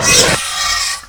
sounds / monsters / chimera / die_2.ogg
die_2.ogg